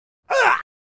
gag2.wav